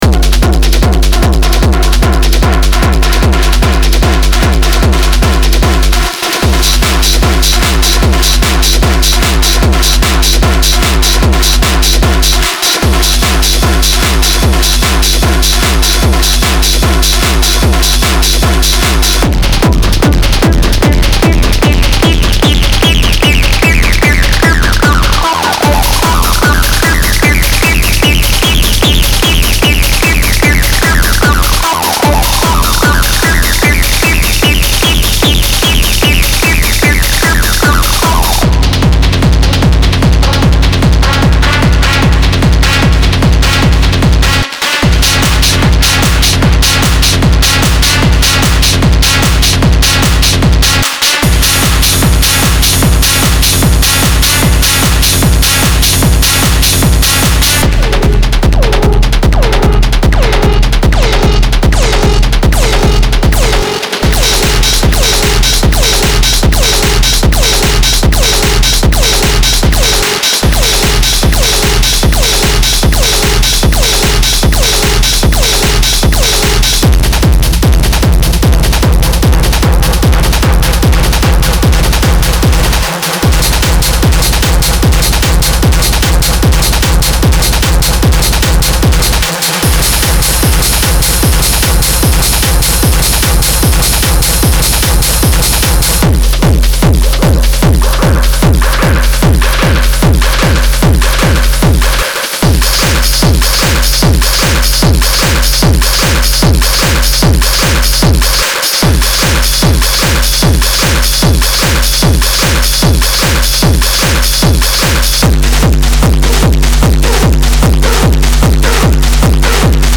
そのサウンドは荒々しくエネルギッシュで、絶えず進化し続けています。
まさに純粋なヘヴィ級のエネルギーです。
デモサウンドはコチラ↓
Genre:Industrial Techno